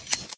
shear.ogg